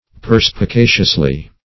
perspicaciously - definition of perspicaciously - synonyms, pronunciation, spelling from Free Dictionary
[1913 Webster] -- Per`spi*ca"cious*ly, adv.
perspicaciously.mp3